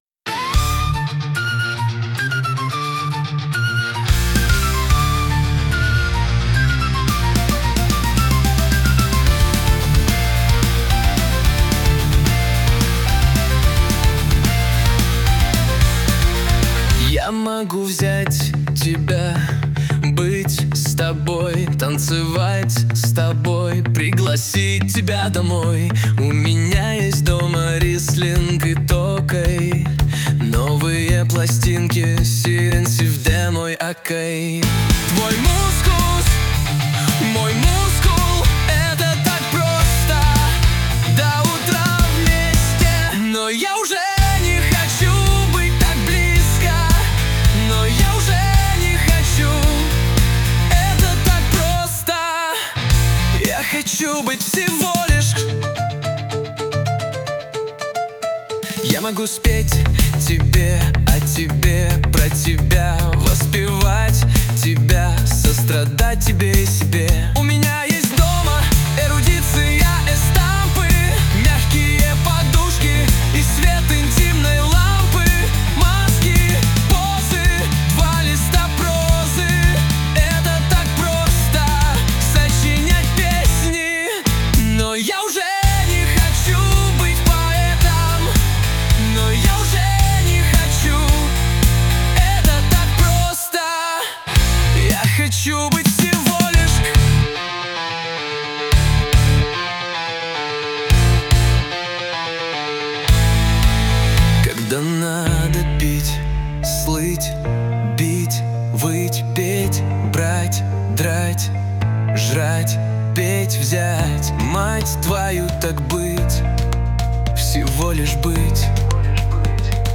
RUS, Romantic, Lyric, Rock, Folk, Indie | 03.04.2025 20:50